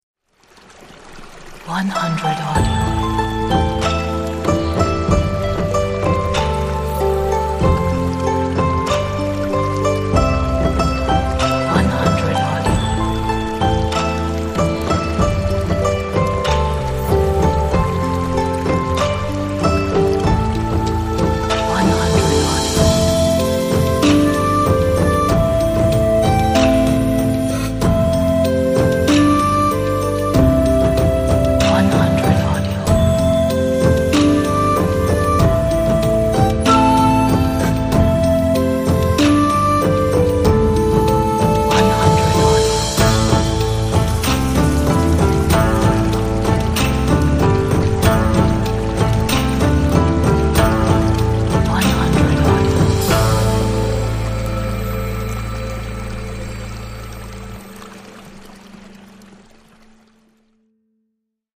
唯美动人的中国风，展现中国江山的美好，水墨般的华夏大地，处处是风景。